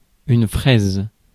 Ääntäminen
IPA : /ˈnɪp(ə)l/